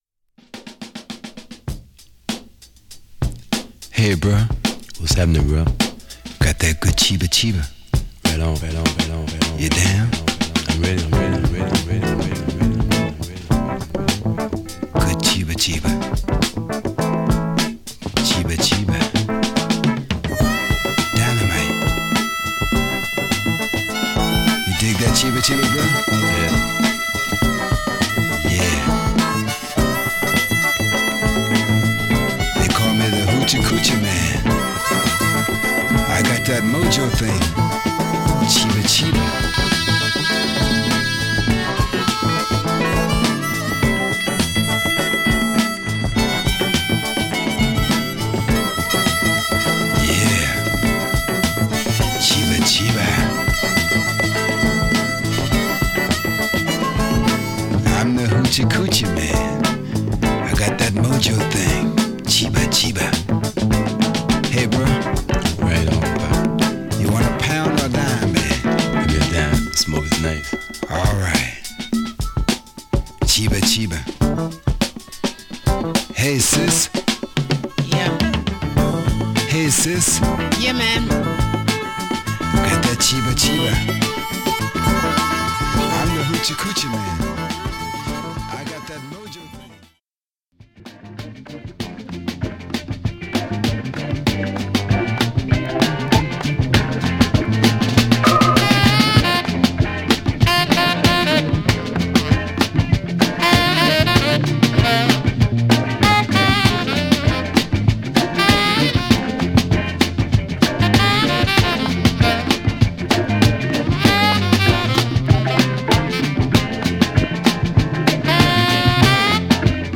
JAZZFUNK